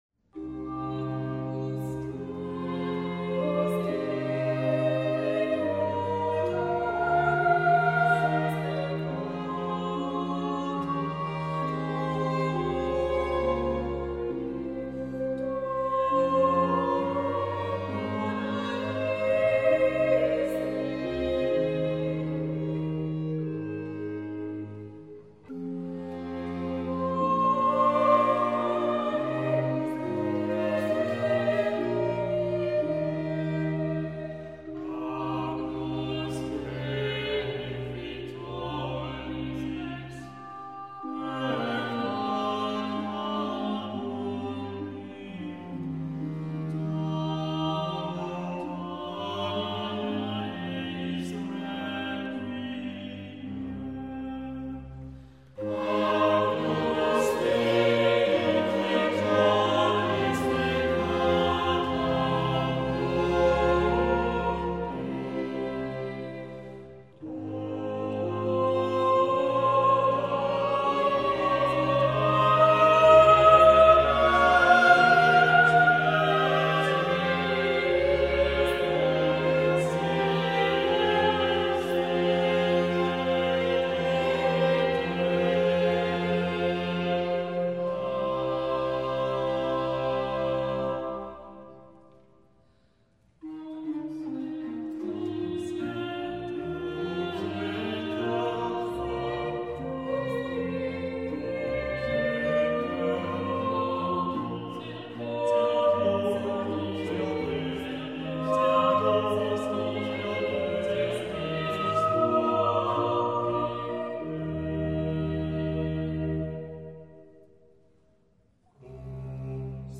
* J. J. Fux / Requiem for emperor Karl (Charles) VI. 7 singers, Baroque string quintet, 2 trombones, positive organ